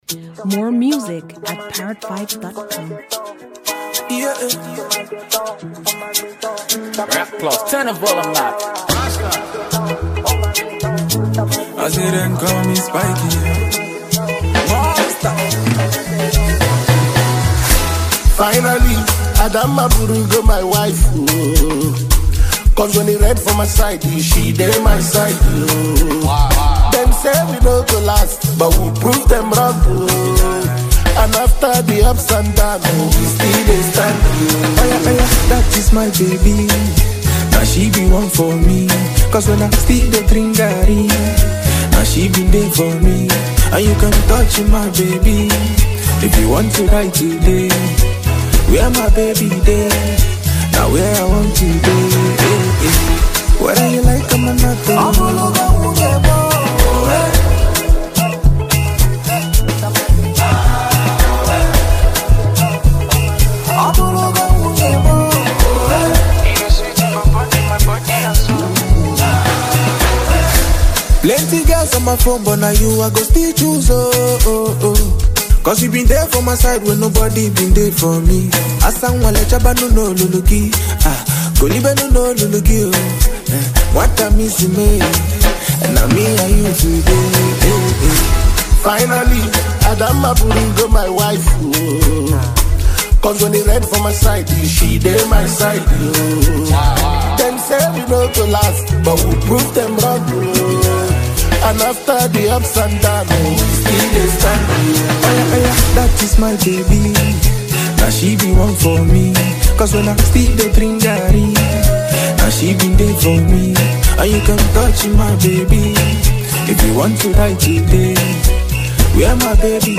wonderful melodious single